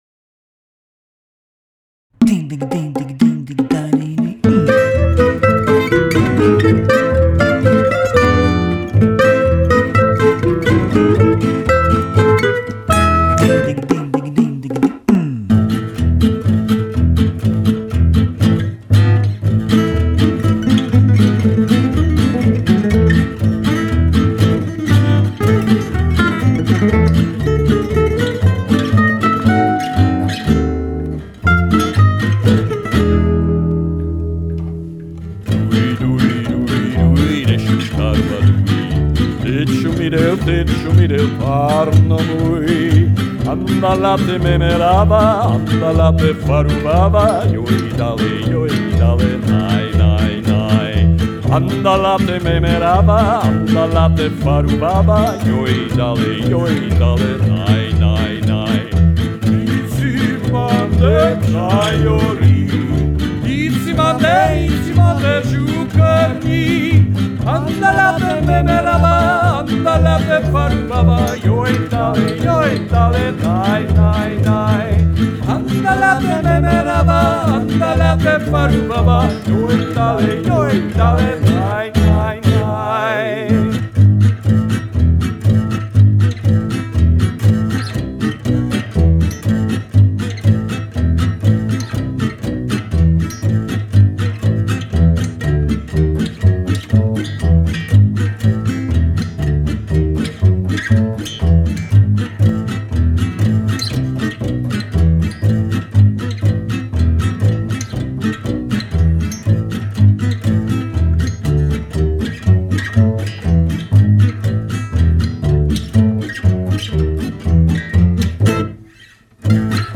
A version with variations in harmonies and lyrics: